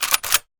gun_shotgun_cock_05.wav